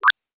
Holographic UI Sounds 112.wav